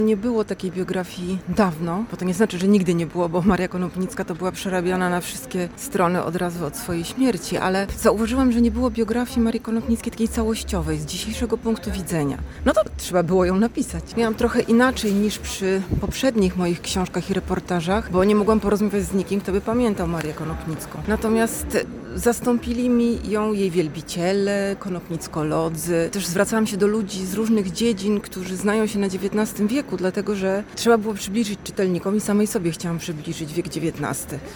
W czwartek, 6 listopada, w Poczytalni na Dworcu odbyło się spotkanie z Magdaleną Grzebałkowską – znaną reporterką i biografistką.
Na zakończenie autorka opowiedziała, co zainspirowało ją do stworzenia biografii Marii Konopnickiej: